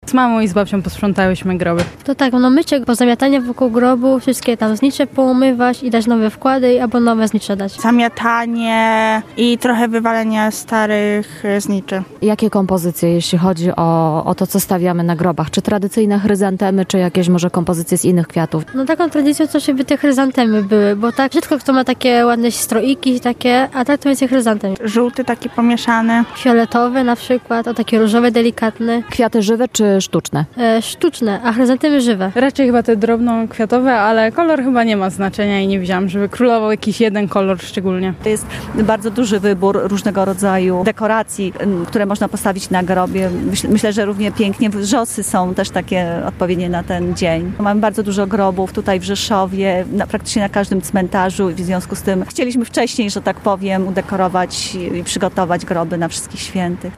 Z mieszkańcami Rzeszowa rozmawiała